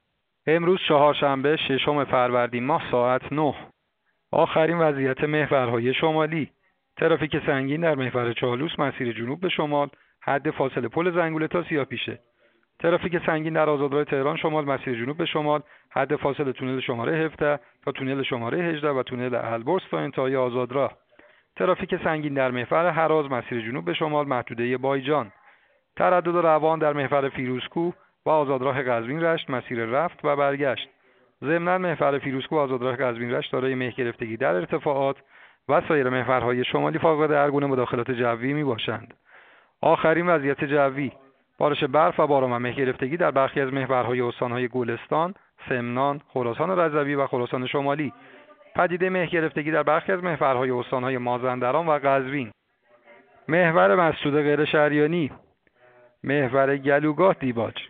گزارش رادیو اینترنتی از آخرین وضعیت ترافیکی جاده‌ها ساعت ۹ ششم فروردین؛